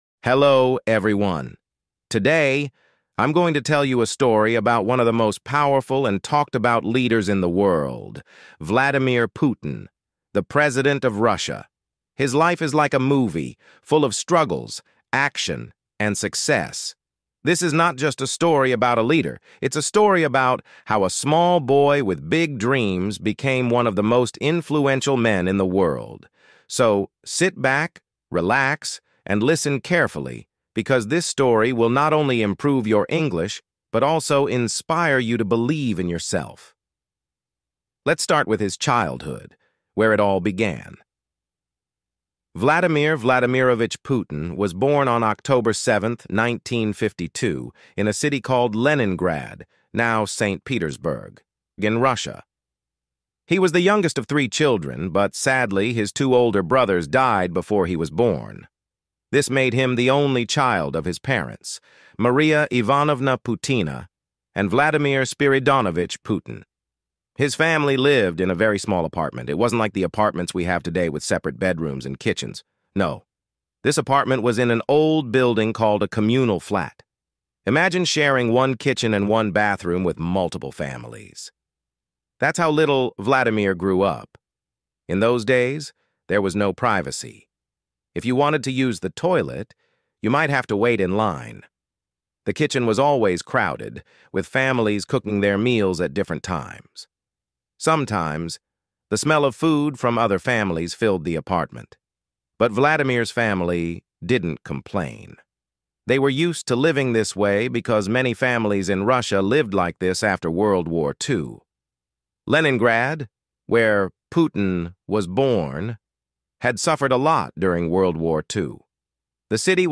Vladimir Putin’s Leadership Story Told in Clear, Neutral English